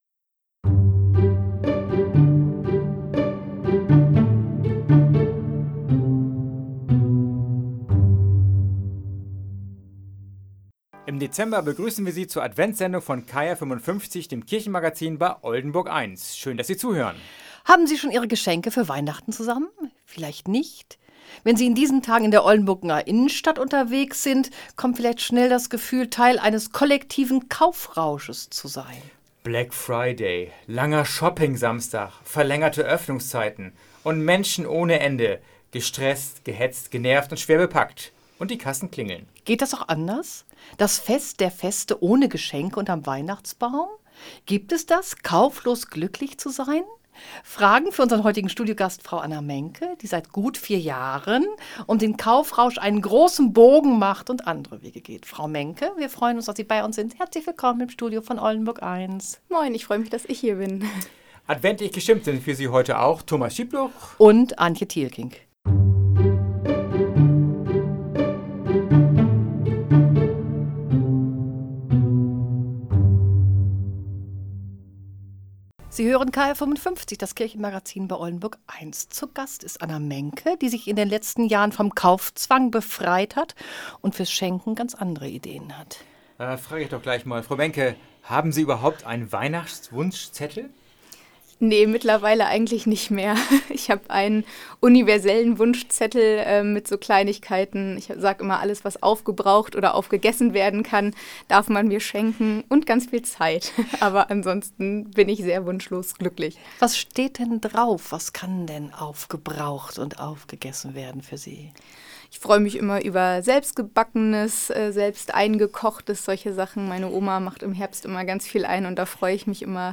Geistlicher Snack